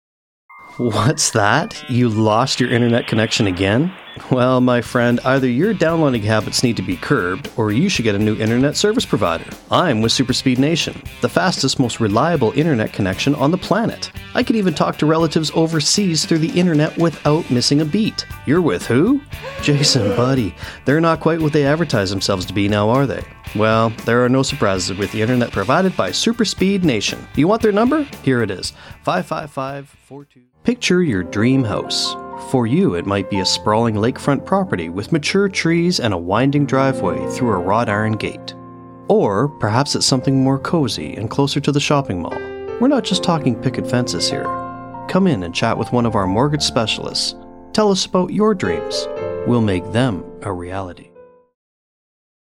Male
English (North American)
Yng Adult (18-29), Adult (30-50)
Looking for that smooth calming voice for your voiceover?
Main Demo